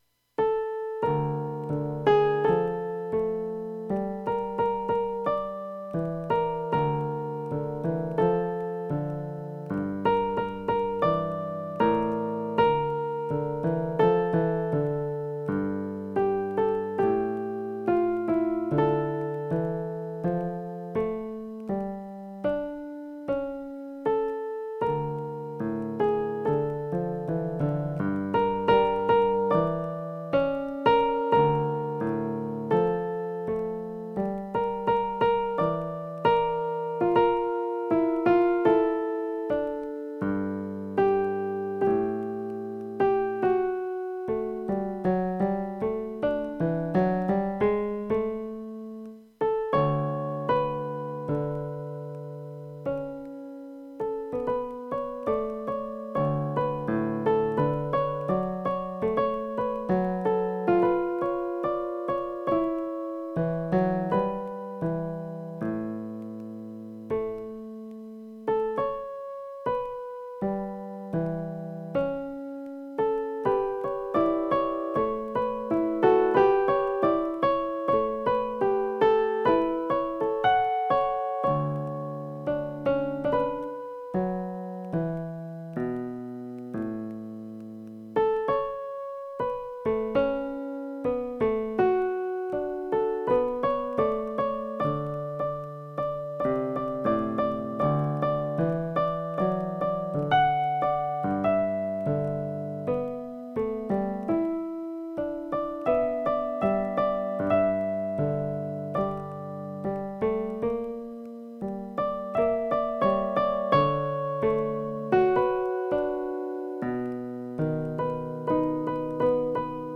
Partitura para piano / Piano score (pdf)
nosotros-piano.mp3